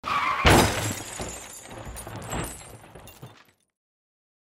دانلود آهنگ تصادف ماشین 3 از افکت صوتی حمل و نقل
دانلود صدای تصادف ماشین 3 از ساعد نیوز با لینک مستقیم و کیفیت بالا
جلوه های صوتی